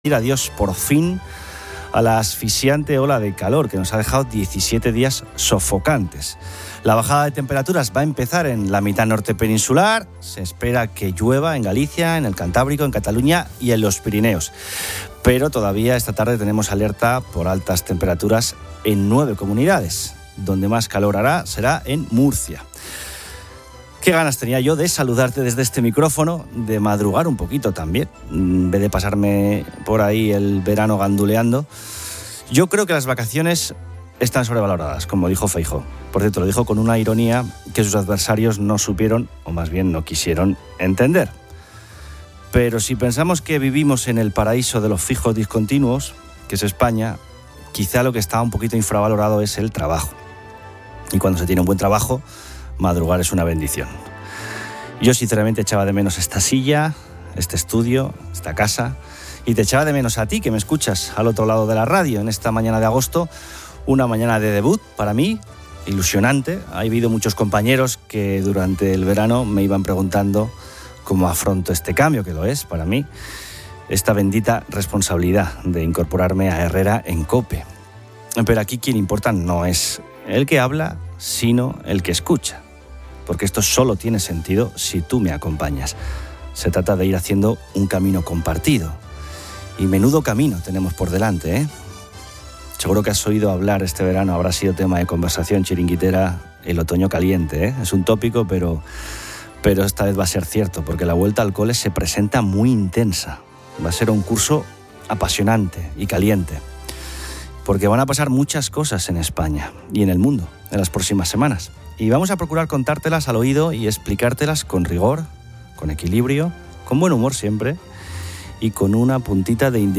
El presentador, de vuelta de vacaciones, critica la excesiva duración de los veraneos políticos.